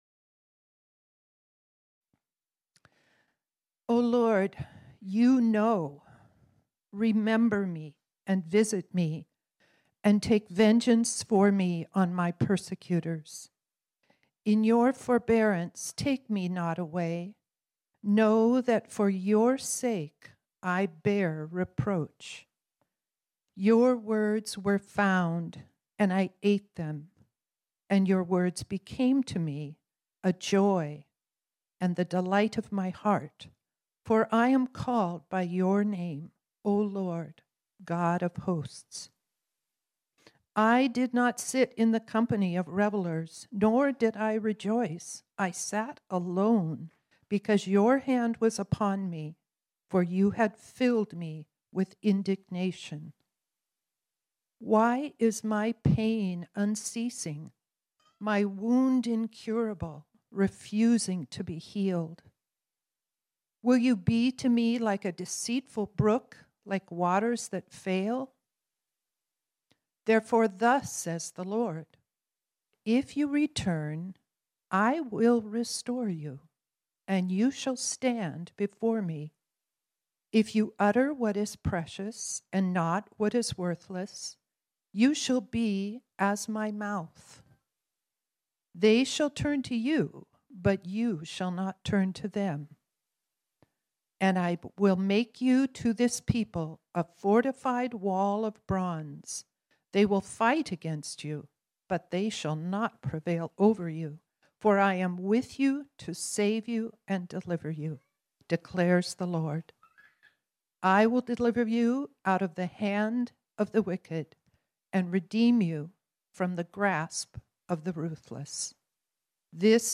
This sermon was originally preached on Sunday, May 29, 2022.